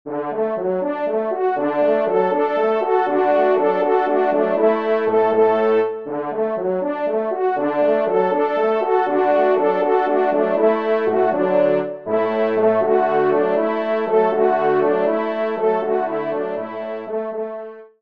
Genre :  Divertissement pour Trompes ou Cors
ENSEMBLE